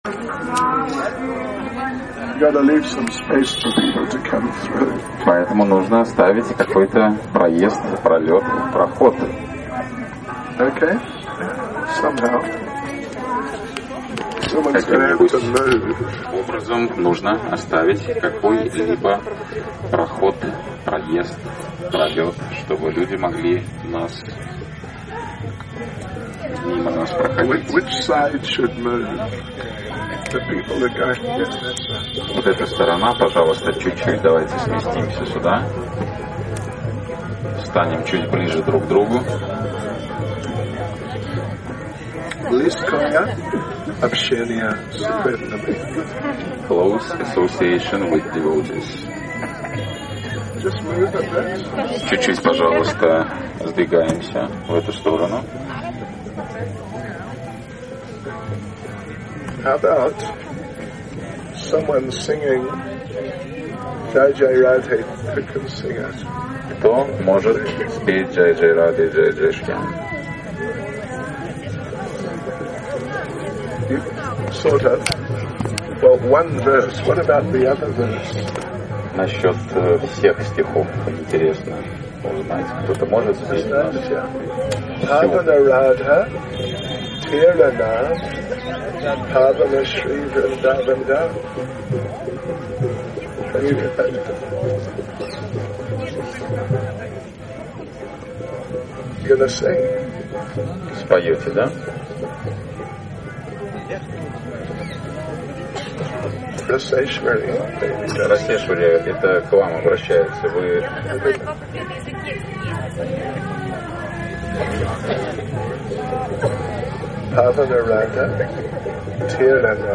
38 12-November 2019 Vraja Mandala Parikrama Govardhan Kirtan